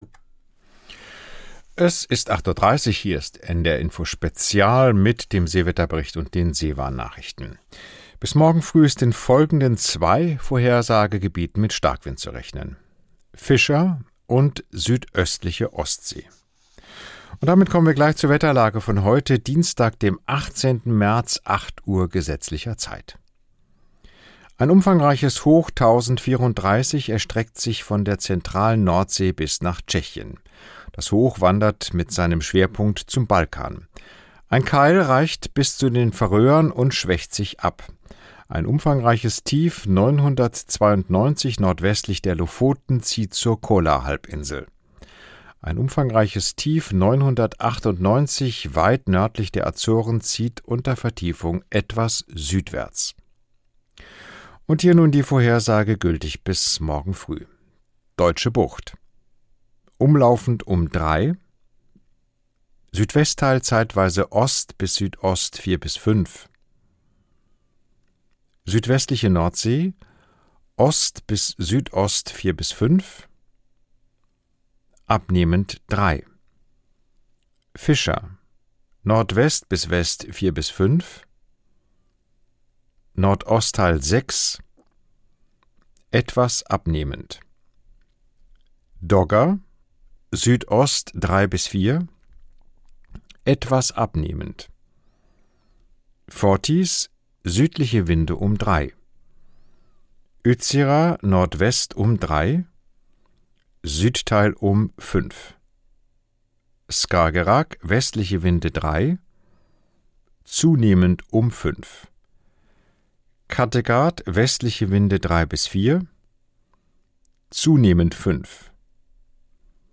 … continue reading 15 つのエピソード # Tägliche Nachrichten # Nachrichten # NDR Info Spezial